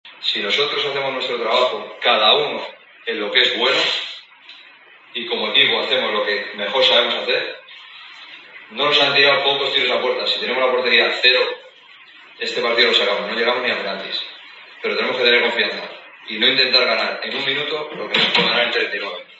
Y ‘Así Somos’ se coló en ese vestuario para dejar constancia de los ánimos insuflados por el técnico en sus jugadores.